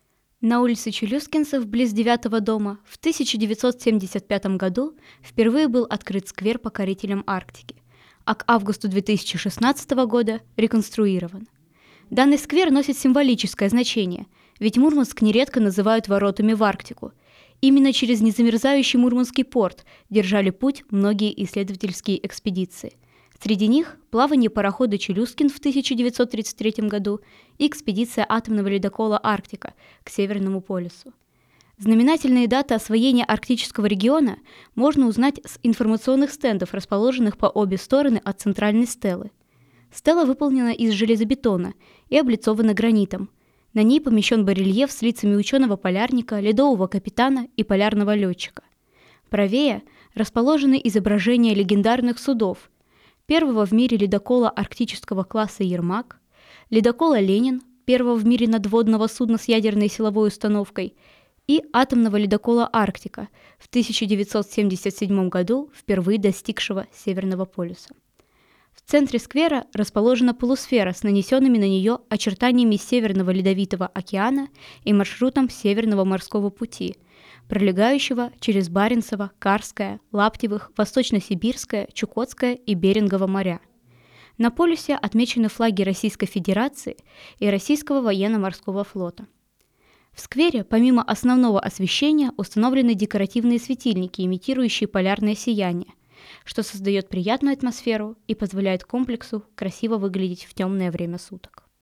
Предлагаем послушать новую аудиоэкскурсию в рамках библиотечного волонтерского проекта «51 история города М»